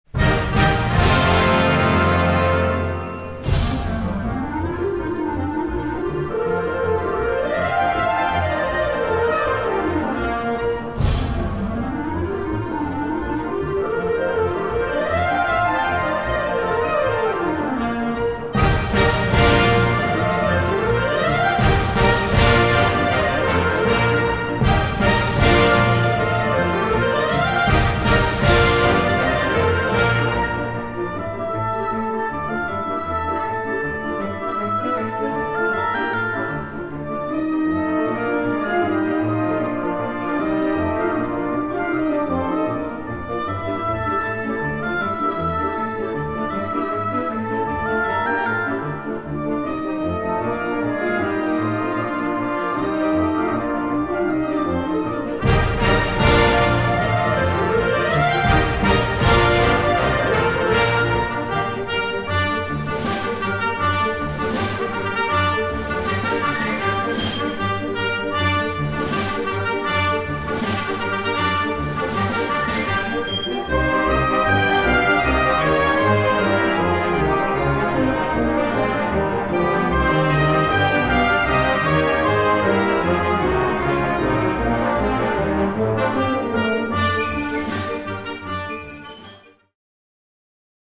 6:00 Minuten Besetzung: Blasorchester Zu hören auf